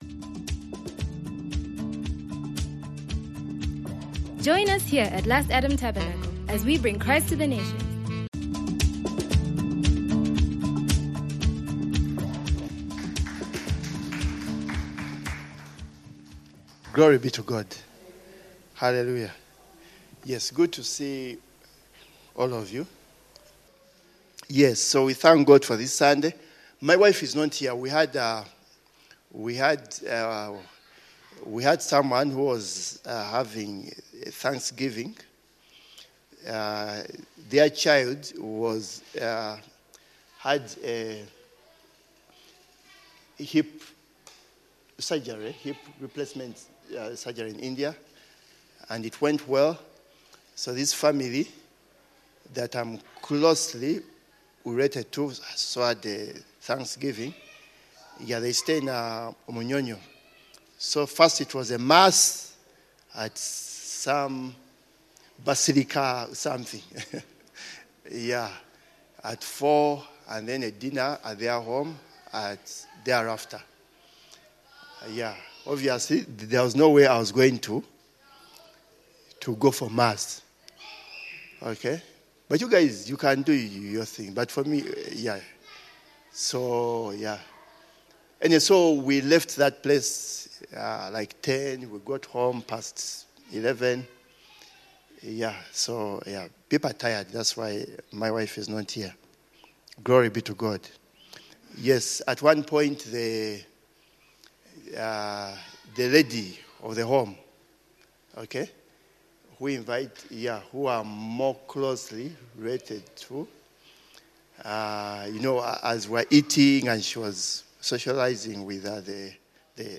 It is by prayer that we cast our cares upon the Lord, and also request for what we want or need. This teaching is instructive in how to pray right so as to effectively cast our cares upon the Lord, and also receive answers to our requests.